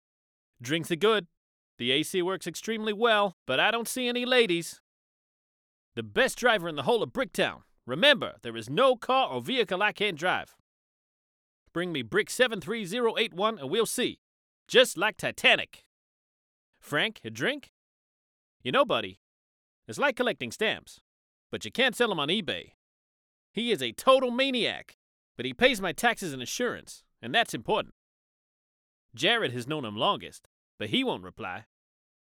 English (International)
Commercial, Natural, Versatile, Friendly, Corporate